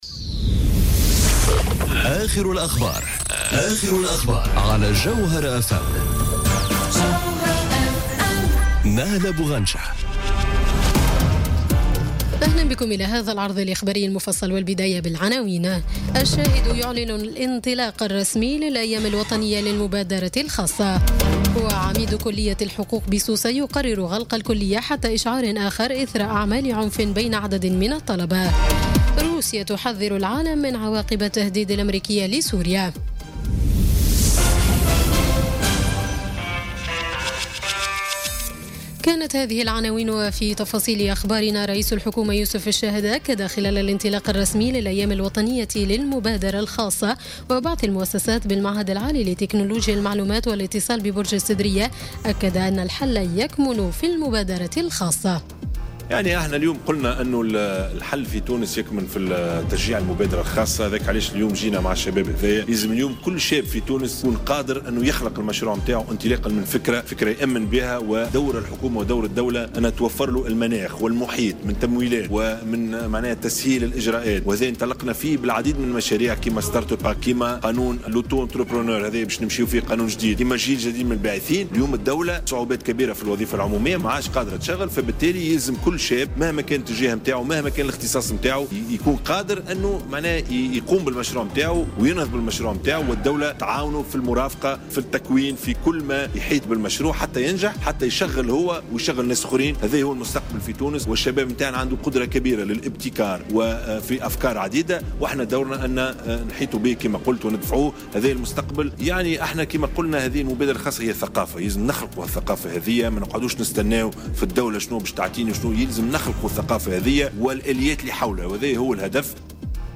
نشرة أخبار السابعة مساءّ ليوم الخميس 12 أفريل 2018